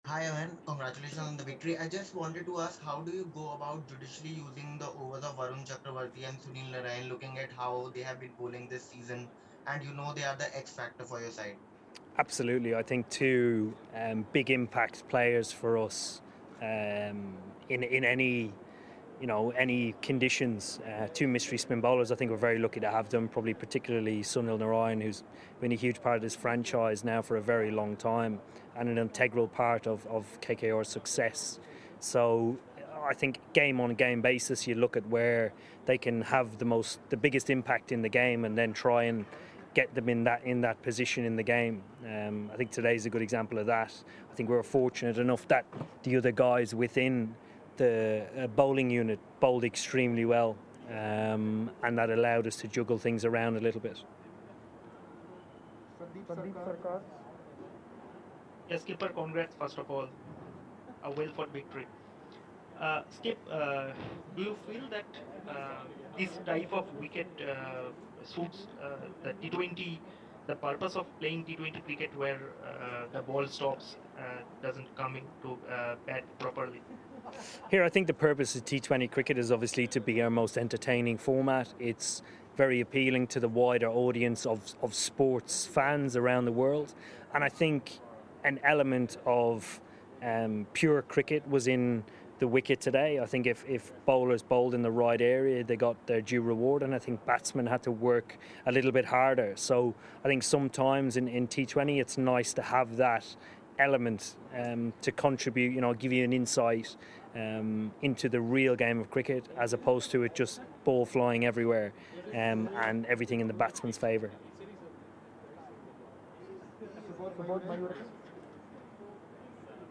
Eoin Morgan of Kolkata Knight Riders addressed the media at the end of the game